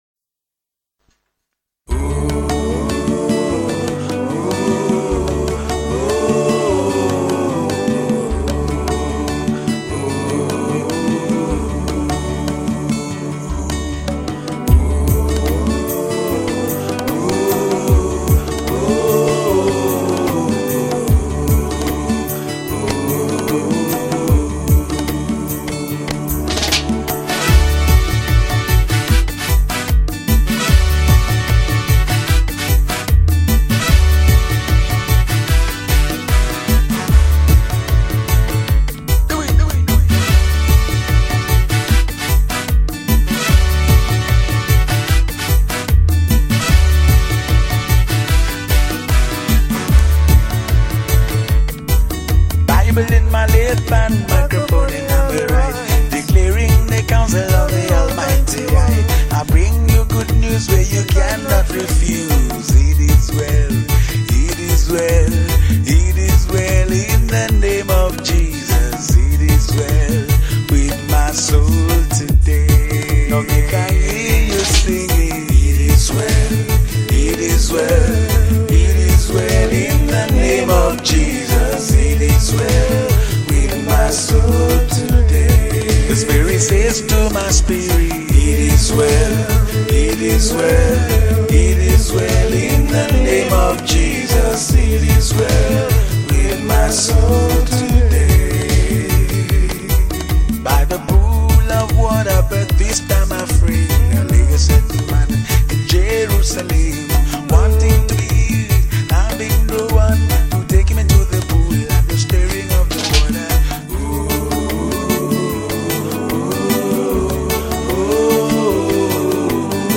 March 12, 2025 Publisher 01 Gospel 0
poet spoken words and reggae gospel artist.